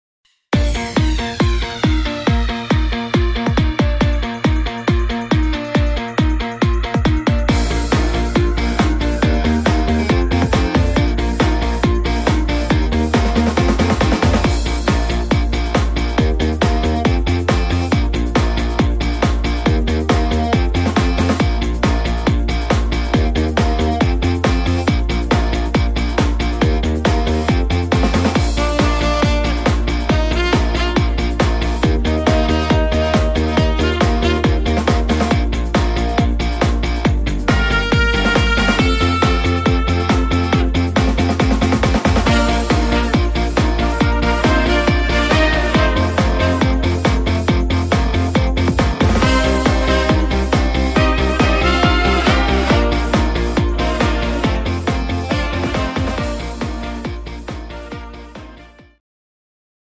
Instrumental Sax